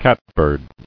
[cat·bird]